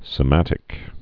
(sĭ-mătĭk)